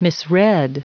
Prononciation du mot misread en anglais (fichier audio)
Prononciation du mot : misread